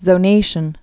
(zō-nāshən)